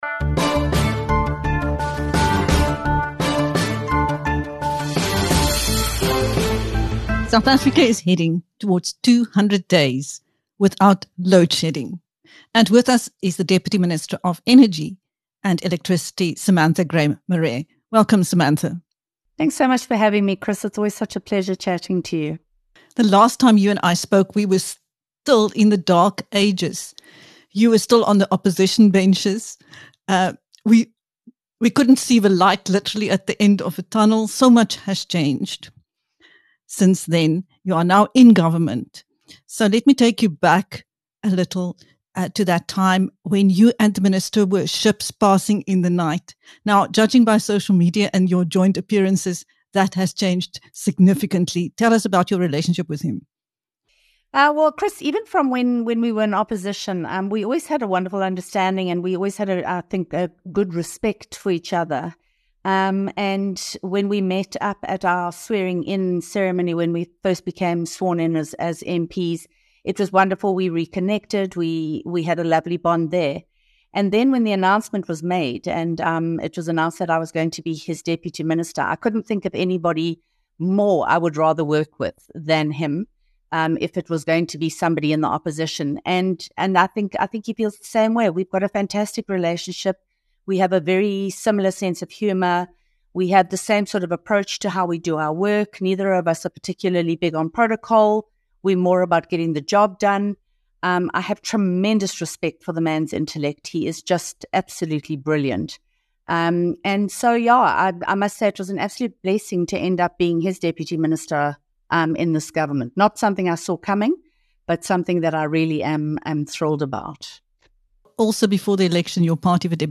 Following the election, she was appointed Deputy Minister of Energy and Electricity in the Government of National Unity (GNU). In this interview with BizNews, she speaks about her great working relationship with Energy and Electricity Minister Kgosientsho Ramokgopa and ESKOM Chair Mteto Nyati.